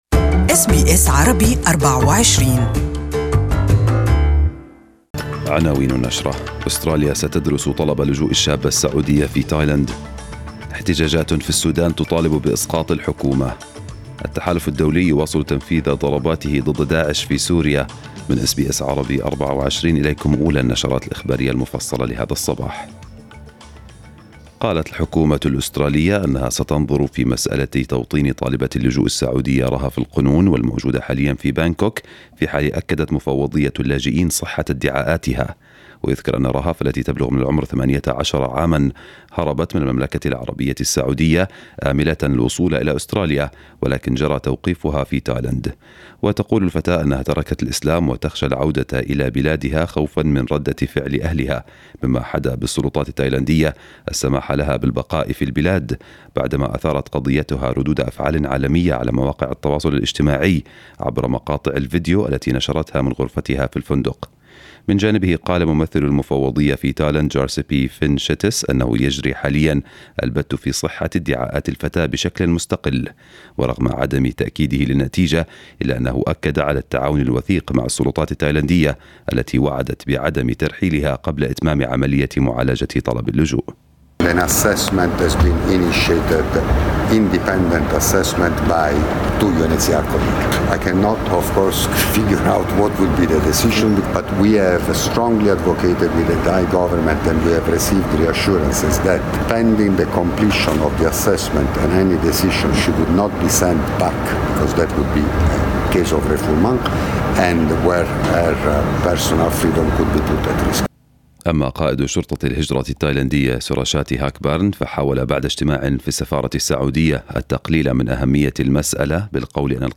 News bulletin of the day